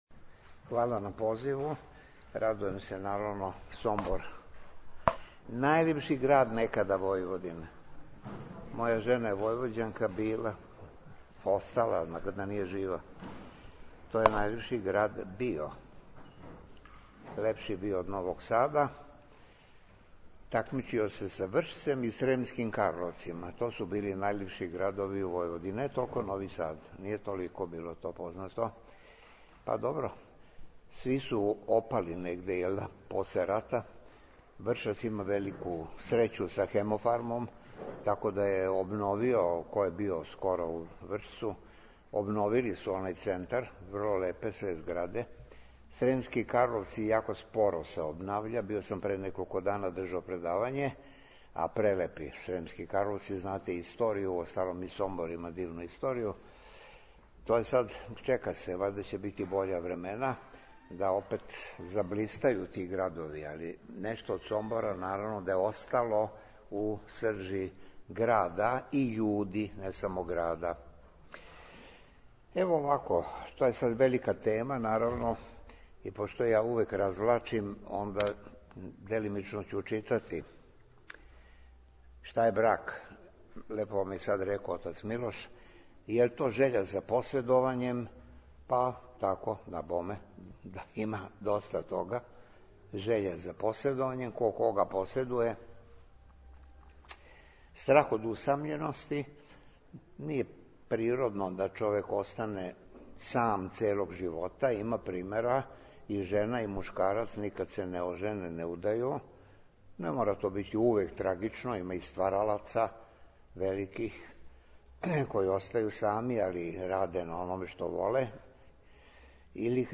Академик Владета Јеротић одржао предавање у Сомбору
У среду, 26. маја текуће године, у свечаној сали Градске куће у Сомбору, у организацији Српске православне црквене општине Сомбор и Дома здравља Др Ђорђе Лазић, академик Владета Јеротић одржао је предавање на тему Шта је брак? Жеља за поседовањем, страх од усамљености или хришћанска Света Тајна.
Звучни запис предавања